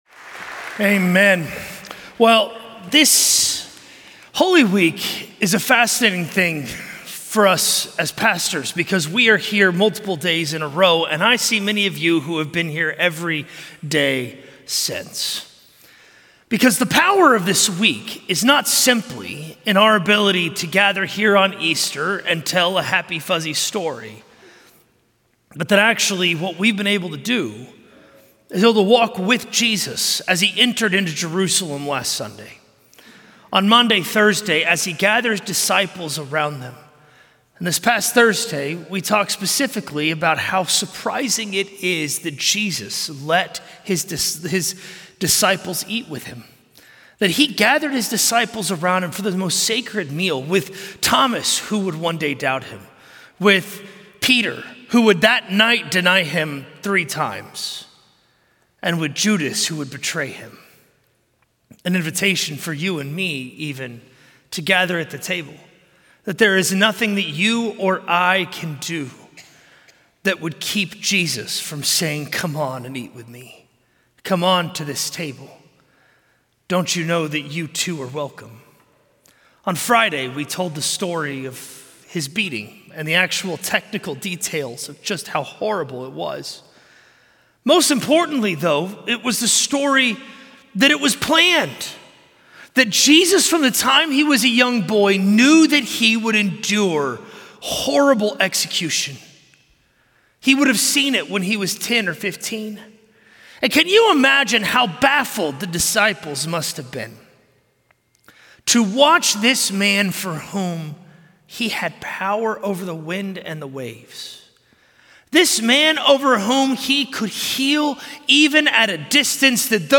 A message from the series "Easter 2025."